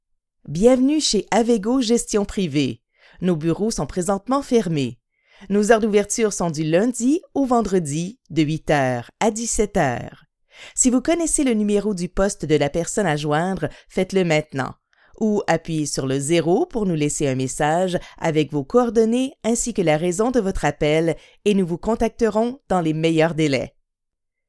Message téléphonique